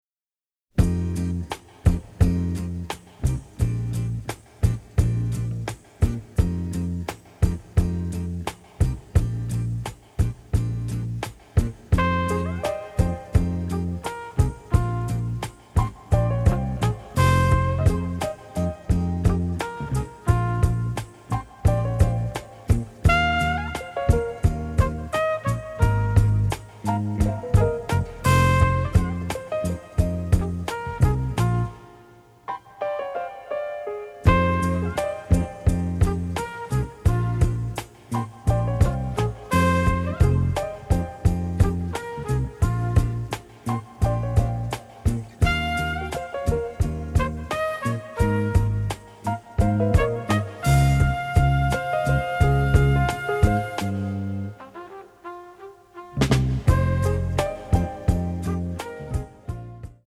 romantic, innovative, masterful score